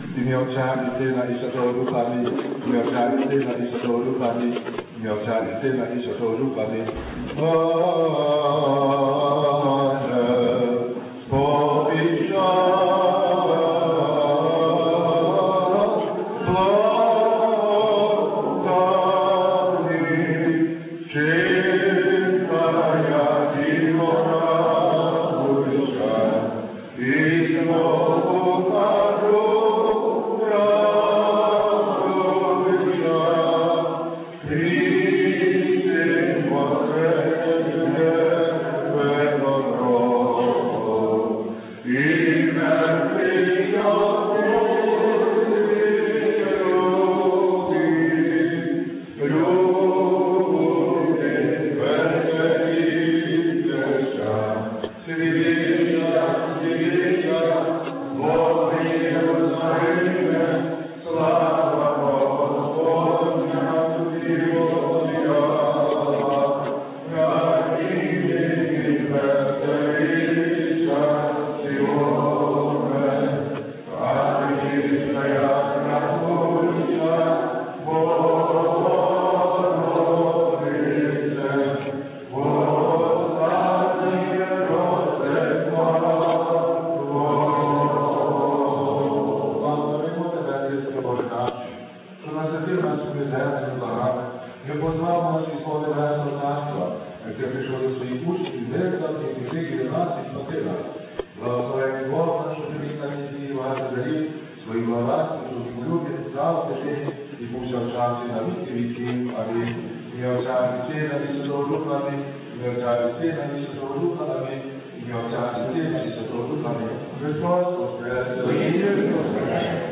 Після вечері та свяченого відбулися виступи дітей та молоді, а також загальна молитва.